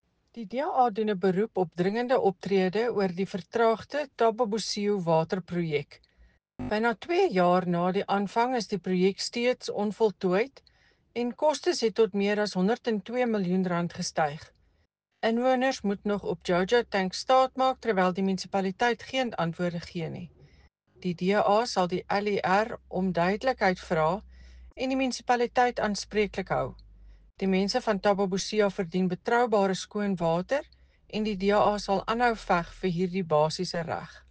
Afrikaans soundbite by Cllr Eleanor Quinta.